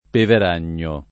[ pever # n’n’o ]